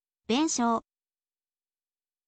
benshou